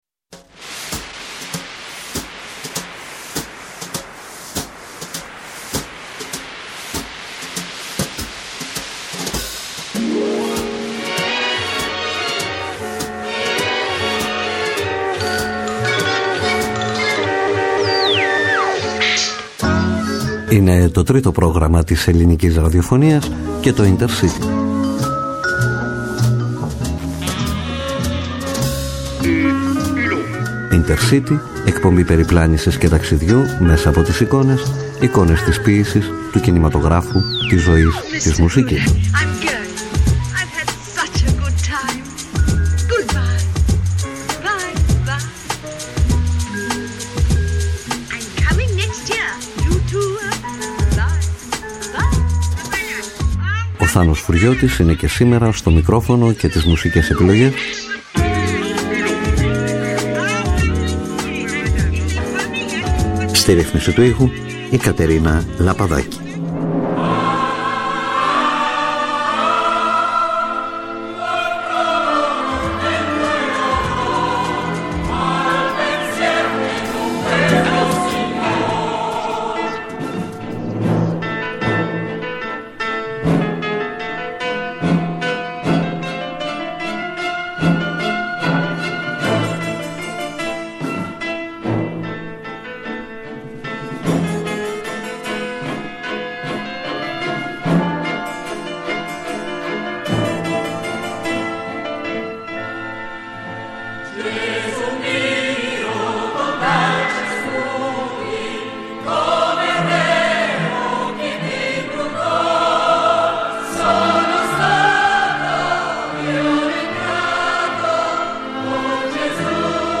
INTERCITY Εκπομπή περιπλάνησης και ταξιδιού μέσα από τις εικόνες: εικόνες της ποίησης, του κινηματογράφου, της ζωής, της μουσικής Με αφορμή ένα θέμα, μια σκέψη, ένα πρόσωπο, ένα βιβλίο, μια ταινία, ένα γεγονός ανακατεύουμε ντοκουμέντα, σελίδες βιβλίων, κείμενα, ήχους, μουσική, λόγο και πάμε ταξίδι σε παρελθόν, παρόν και μέλλον .